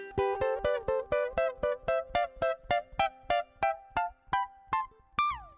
Звук струн Укулеле